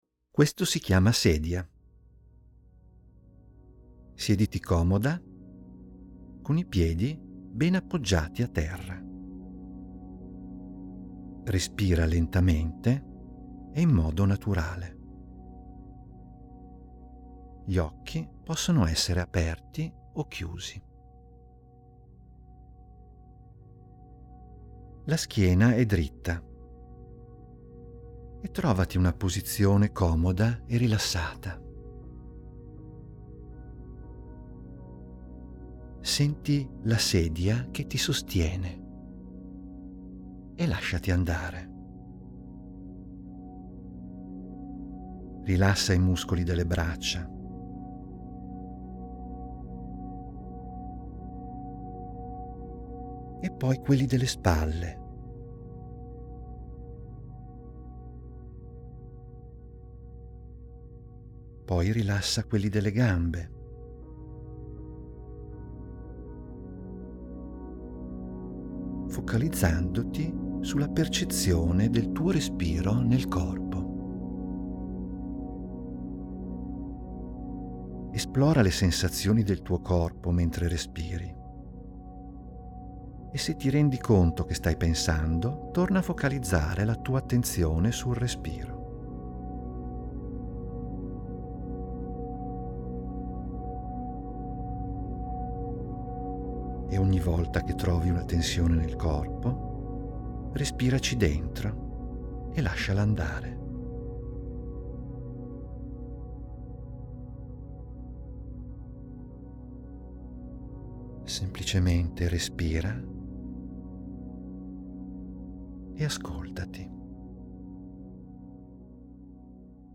In questa sezione trovi degli audio che ti guidano a svolgere delle mini-meditazioni di due minuti.